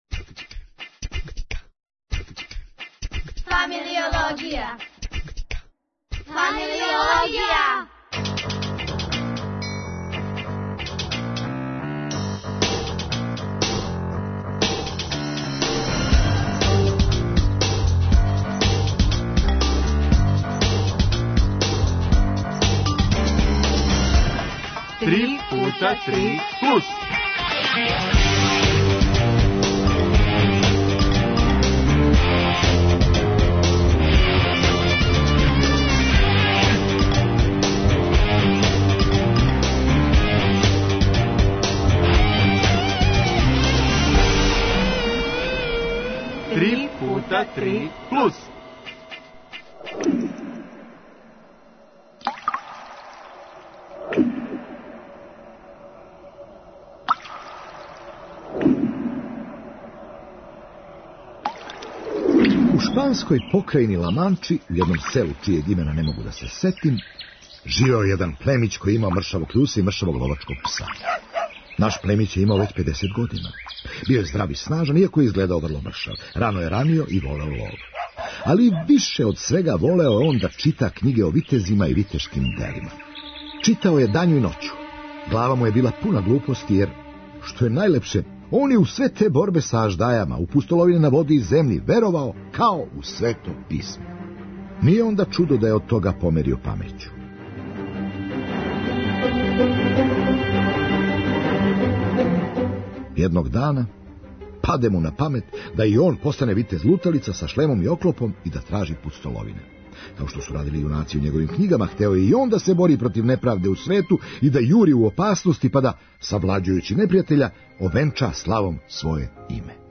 О томе с онима који се "умећу" у друге ликове - младим глумцима.